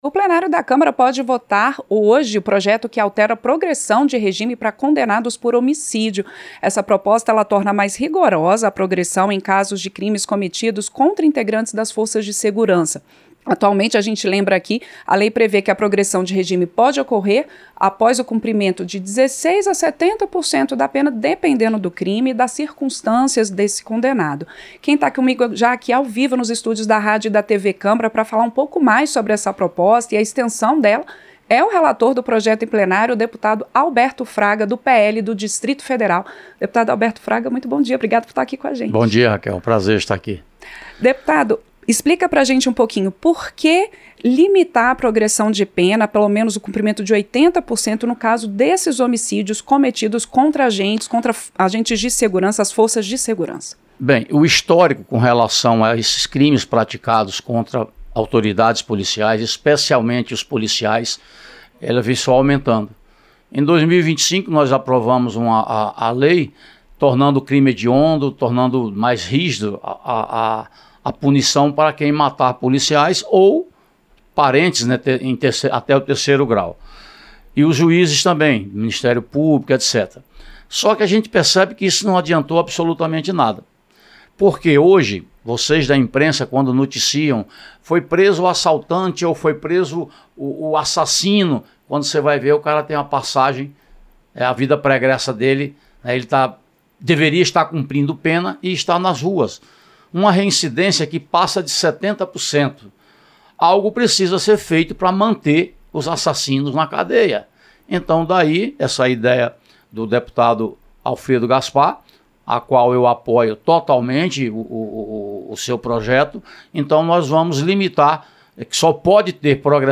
• Entrevista - Dep. Alberto Fraga (PL-DF)
Programa ao vivo com reportagens, entrevistas sobre temas relacionados à Câmara dos Deputados, e o que vai ser destaque durante a semana.